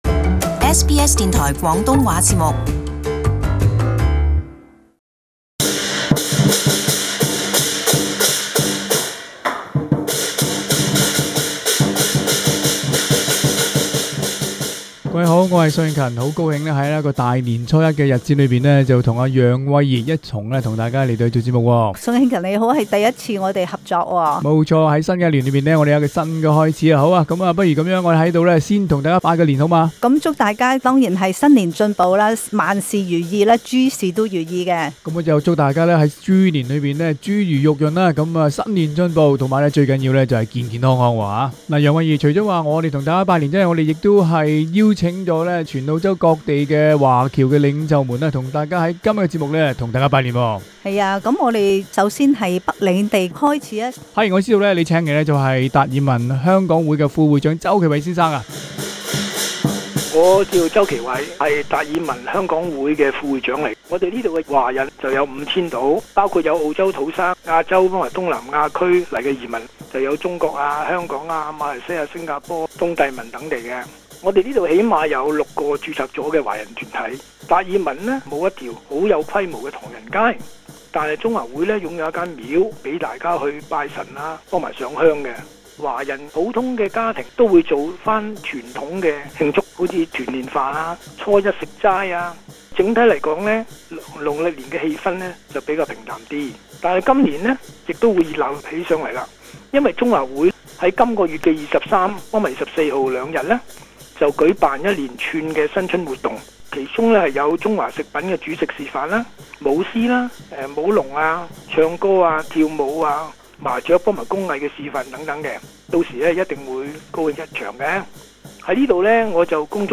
And wish you all a very happy and prosperous Year of Pig.